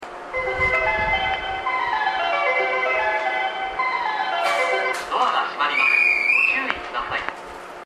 １番線常磐線
発車メロディー途中切りです。